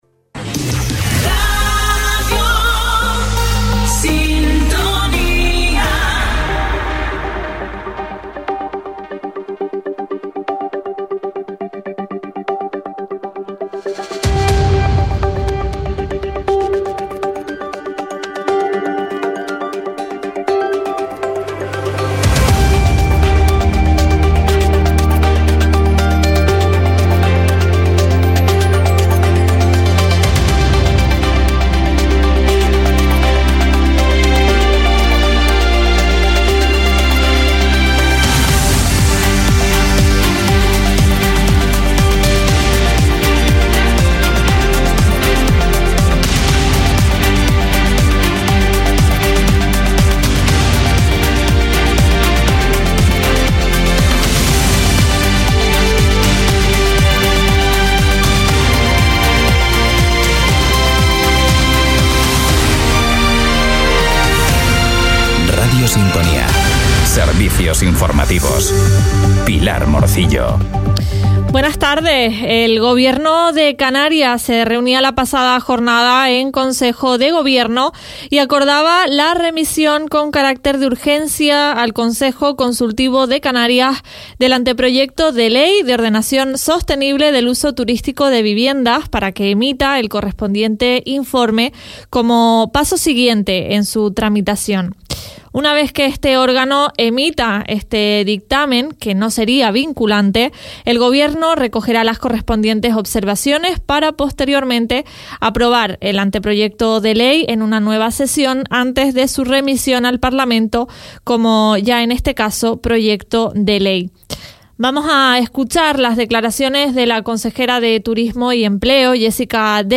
Informativos en Radio Sintonía